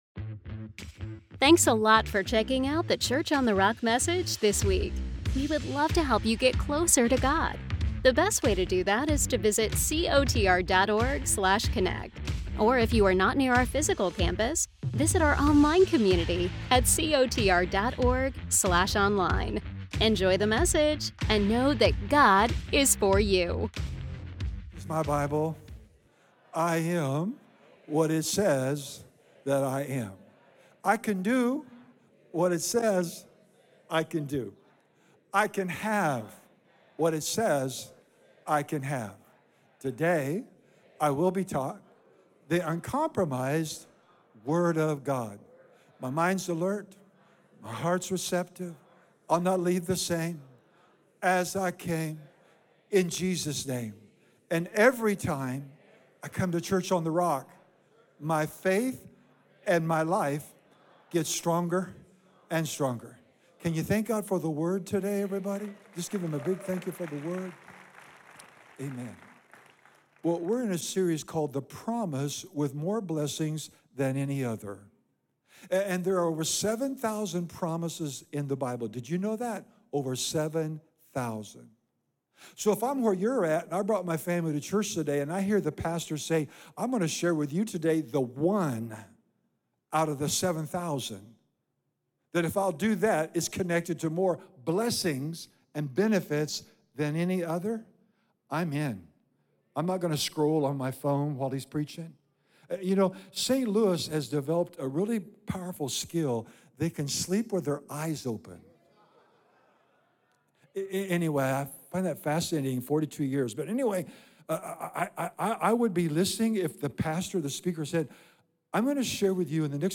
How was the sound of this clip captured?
The Promise With More Blessings Than Any Other - Part 4 - Unlock God’s Protection, Peace, and Wisdom | Church on the Rock | Church on the Rock